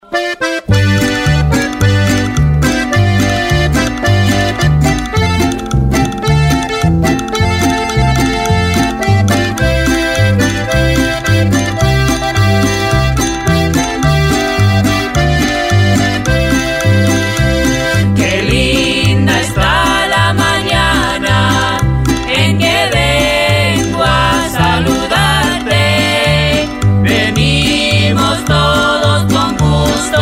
Traditional Spanish Song Lyrics with English Translation